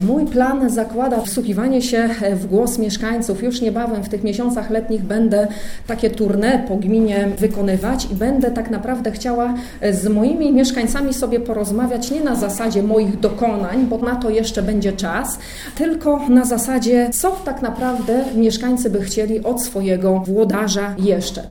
Izabela Bojko będzie ponownie brać udział w wyborach na stanowisko wójta gminy Nowa Sól. Taką informację przekazała dziennikarzom w czasie konferencji prasowej.